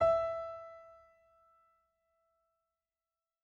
piano-sounds-dev
e4.mp3